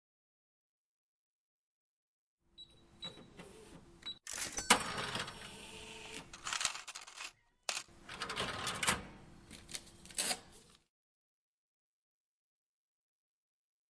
Download Free Cash Register Sound Effects
Cash Register